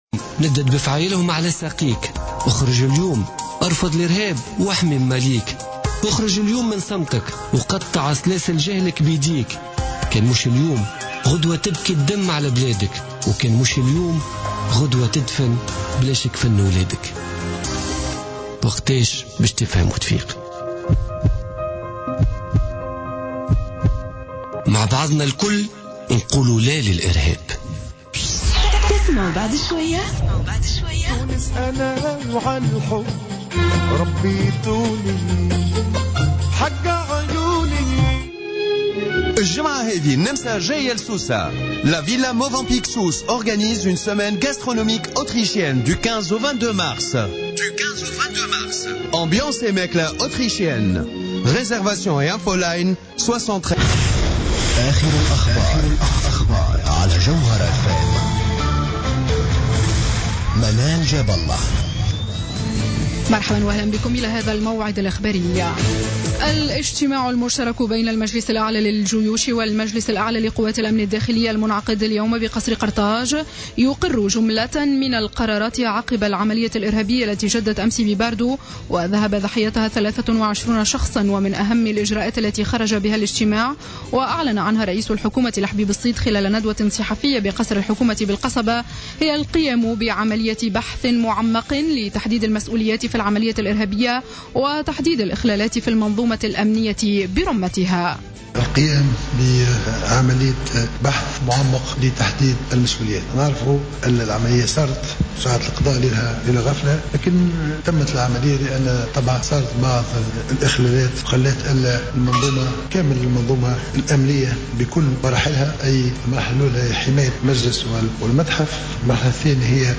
نشرة أخبار السابعة مساء ليوم الخميس 19مارس 2015